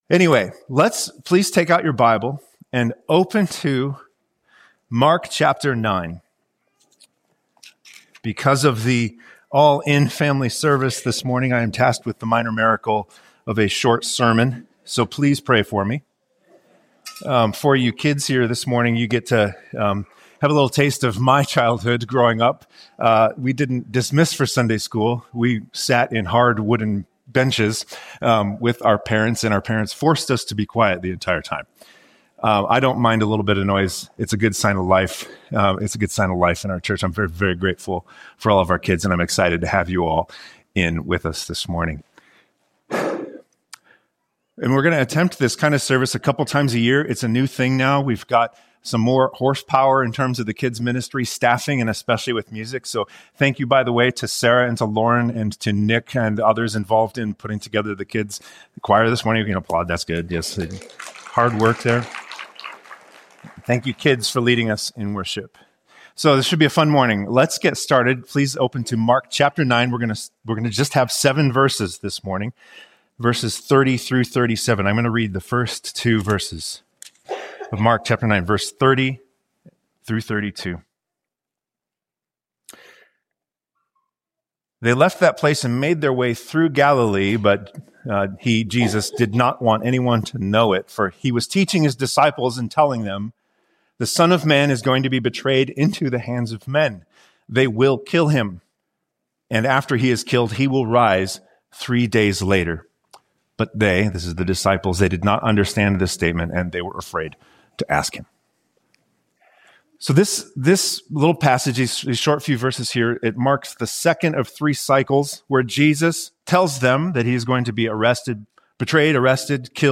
chapter 9 This morning I am tasked with attempting the minor miracle of a short sermon. We’re going to attempt this on every month that has five Sundays, on the fifth Sunday, an all-family service.